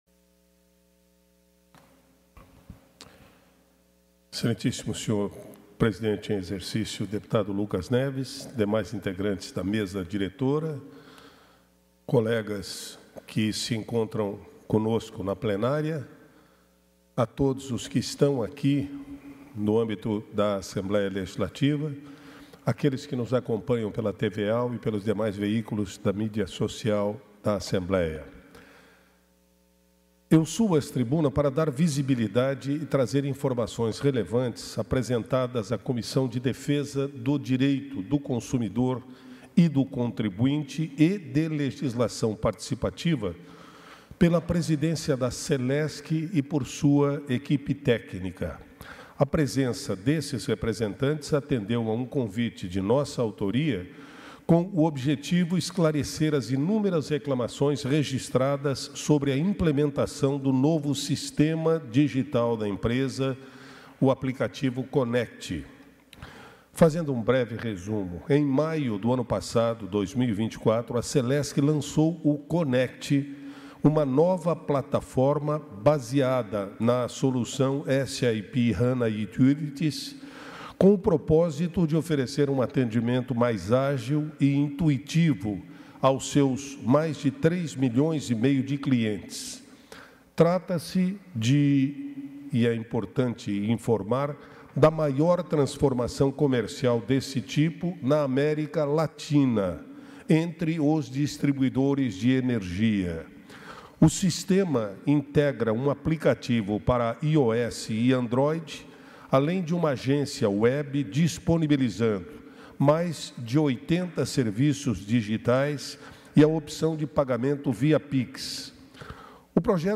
Pronunciamentos da sessão ordinária desta quinta-feira (20)